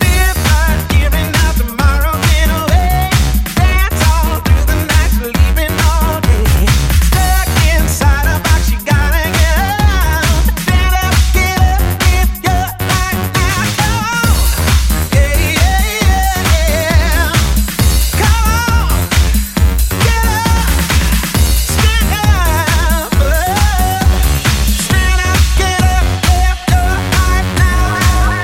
Genere: dance, house, electro, club, remix, 2008